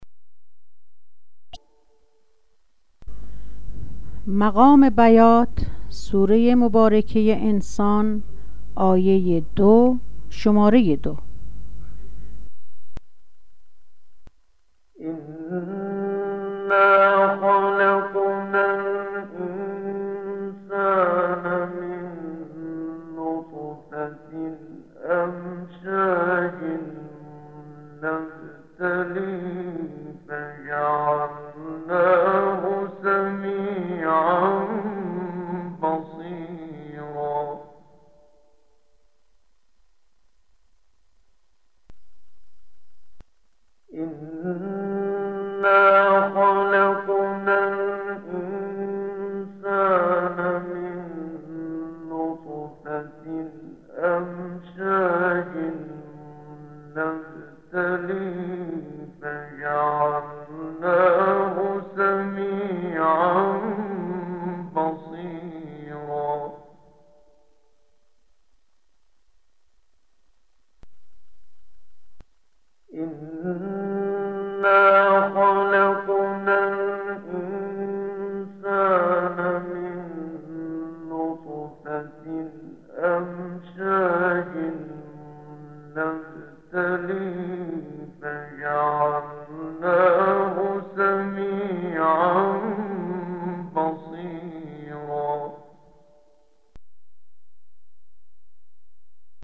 بیات ویژگی منحصر به فرد و به غایت پر سوز و تأثیر گزاری دارد که ضمن ایجاد حزنی خاص در شنونده، وی را به تدبّر دعوت می کند.
🔸آموزش مقام بیات (قرار۲)
👤 با صدای استاد محمد صدیق المنشاوی